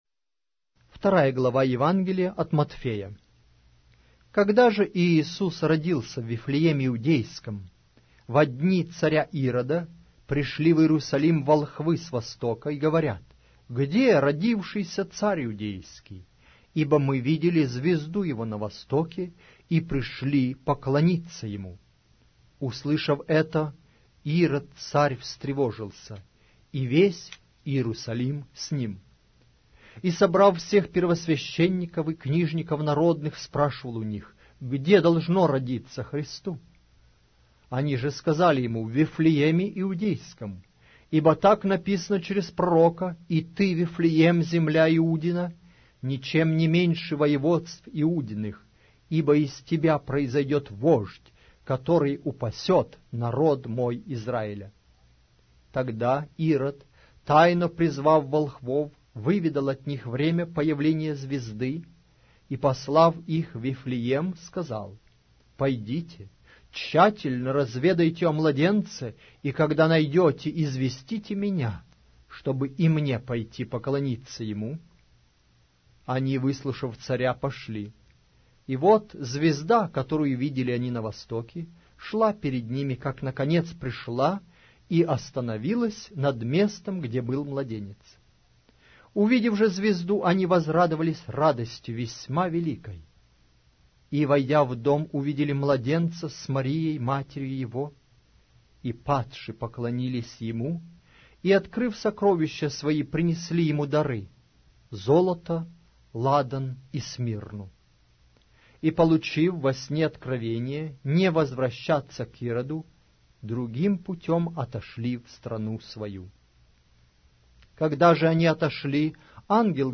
Аудиокнига: Евангелие от Матфея